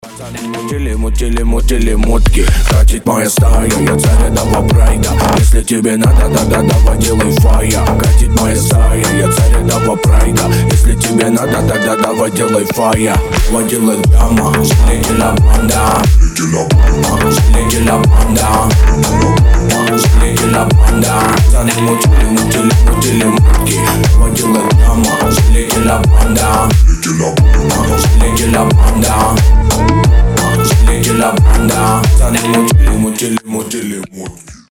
• Качество: 320, Stereo
мощные басы
качающие
G-House
Gangsta